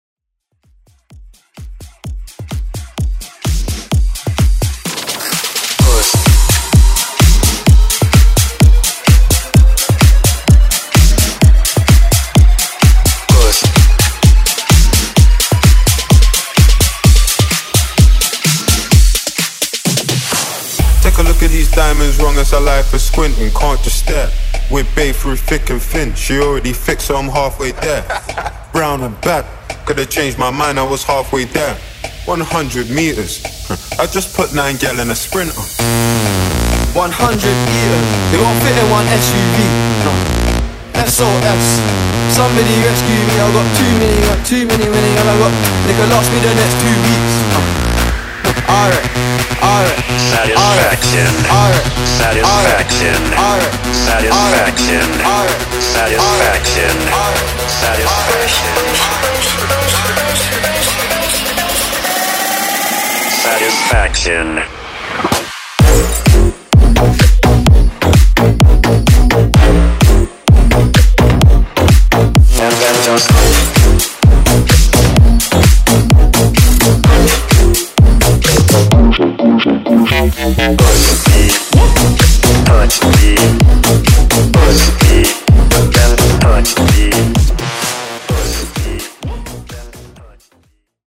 FUTURE HOUSE , MASHUPS Version: Clean BPM: 128 Time